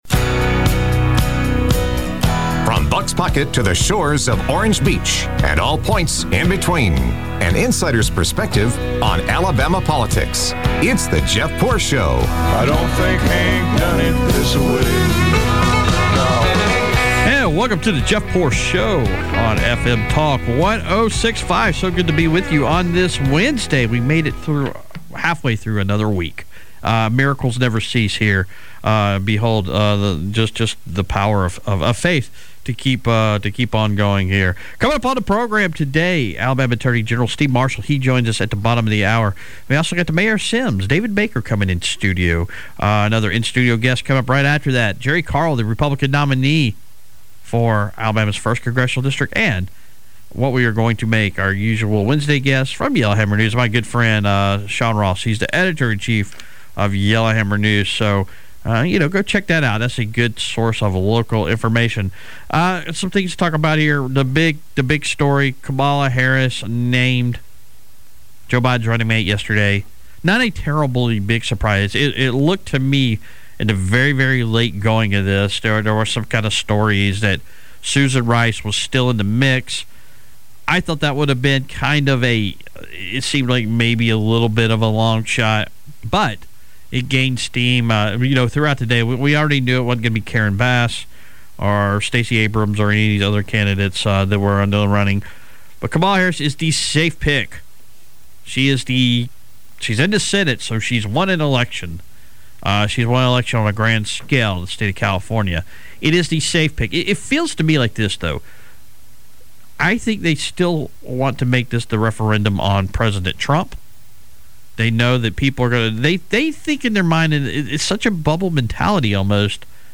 interviews Alabama AG Steve Marshall